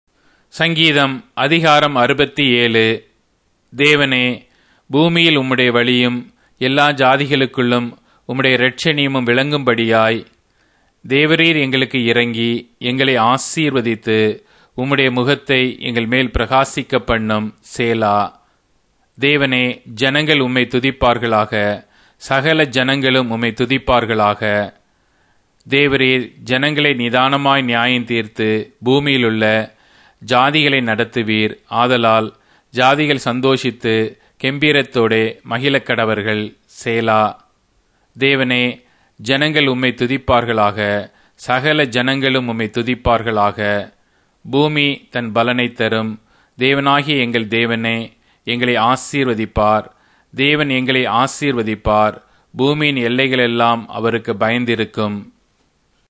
Tamil Audio Bible - Psalms 86 in Tev bible version